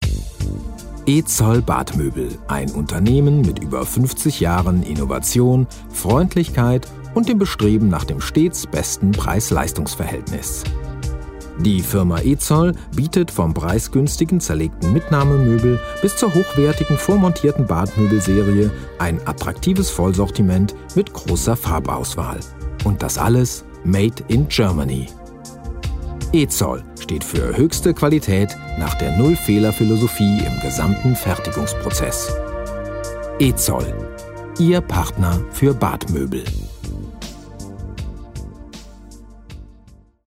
德语样音试听下载
德语配音员（男1）
German_male_DG005-demo.mp3